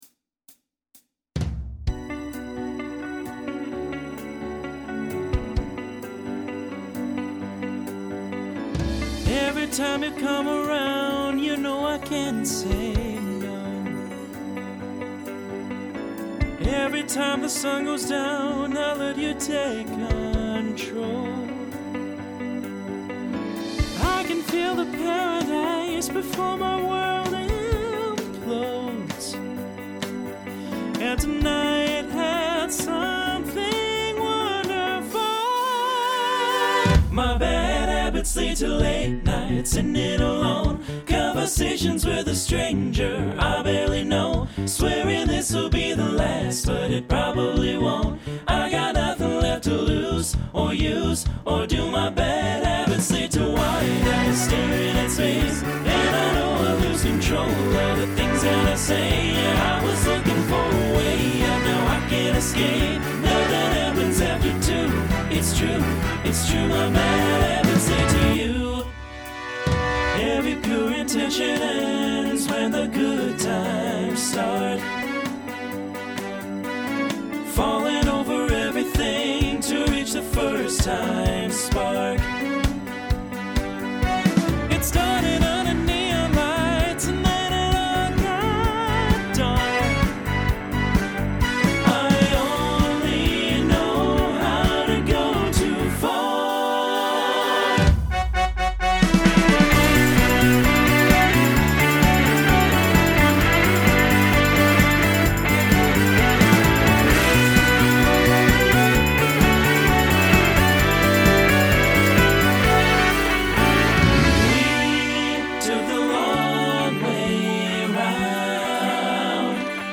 Genre Pop/Dance Instrumental combo
Transition Voicing TTB